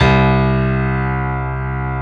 C1-PNO93L -L.wav